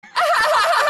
Play, download and share Ninja laughing original sound button!!!!
ninja-laughing.mp3